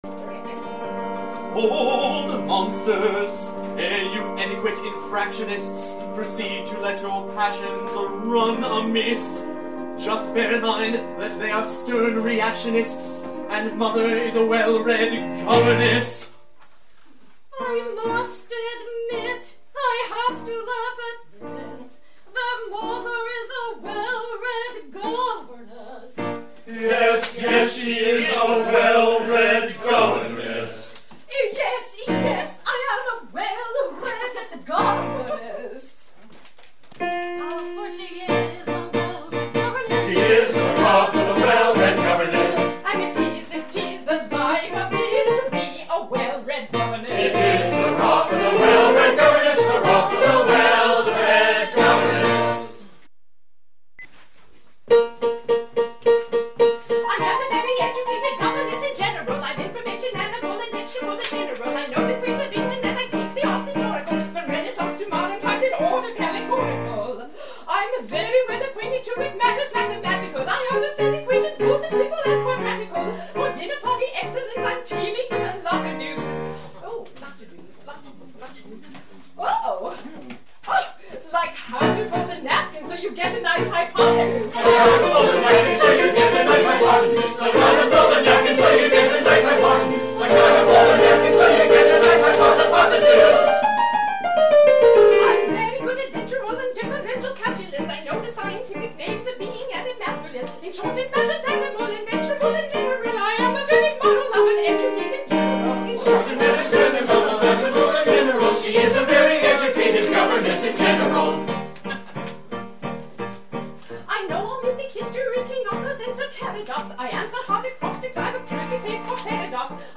Song - Governess Stanley